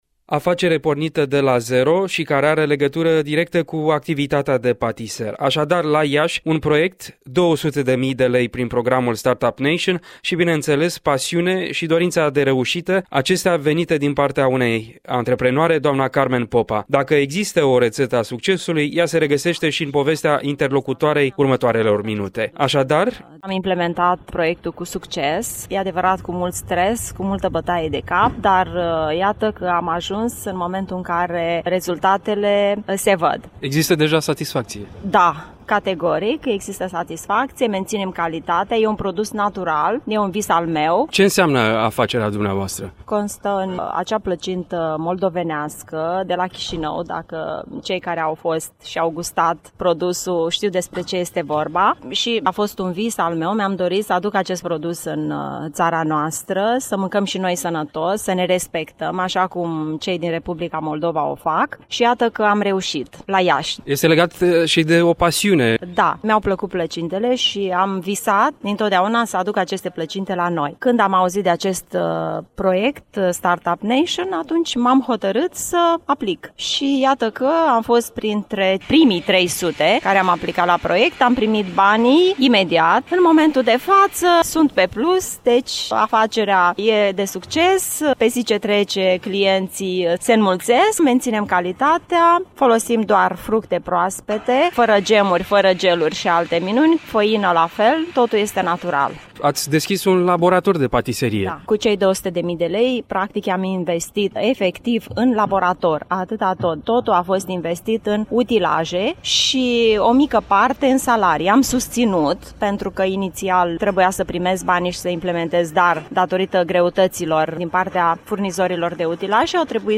Într-o serie de interviuri ce pleacă de la un parteneriat între ministerul de resort și Radio România Regional, la Radio Iași prezentăm reușitele celor care au aplicat pentru a obține fonduri.
Dacă există, o rețetă a succesului ea se regăsește și în povestea interlocutoarei. Un interviu